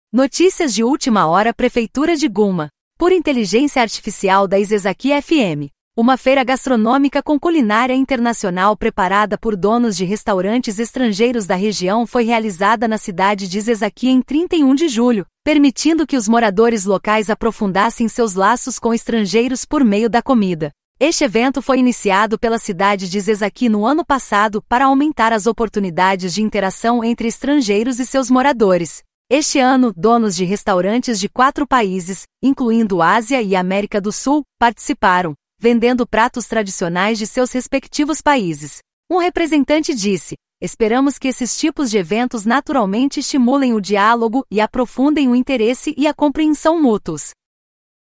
Audio Channels: 1 (mono)